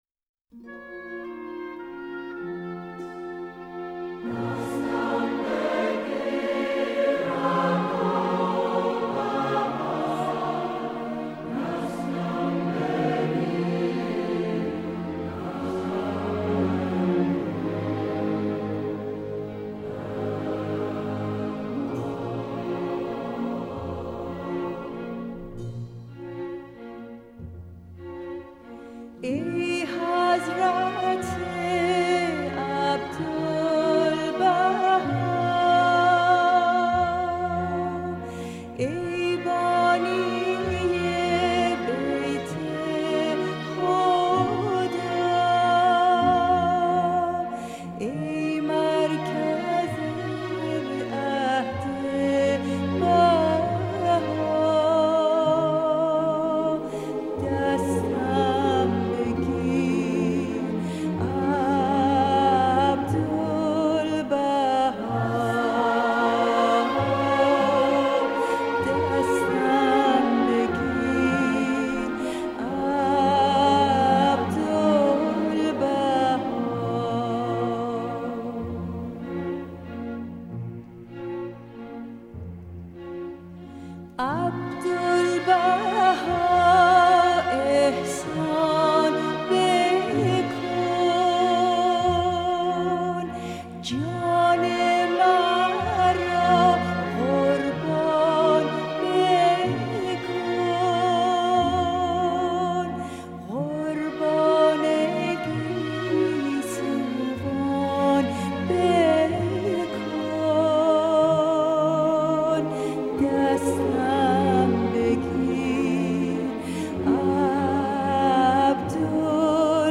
کنگره جهانی دوم بهائیان در نیویورک سال 1992